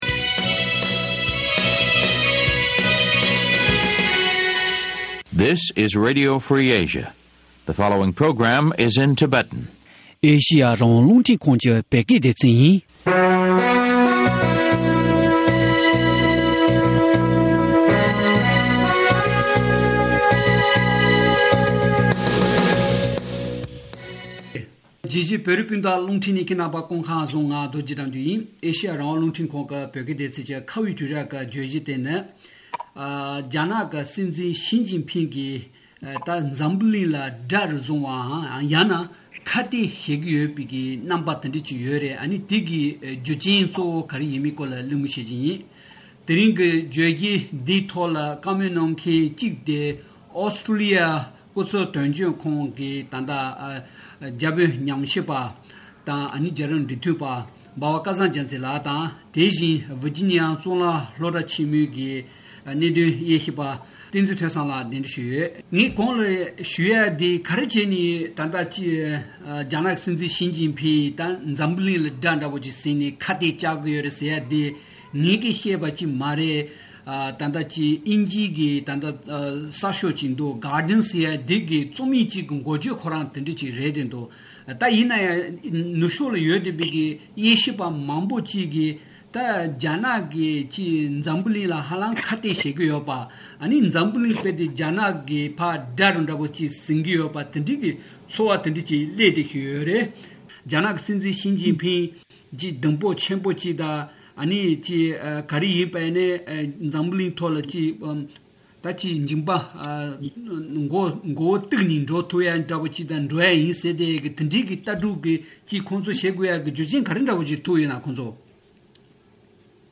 གླེང་མོལ་ཞུས་པ་ཉན་རོགས་ཞུ།།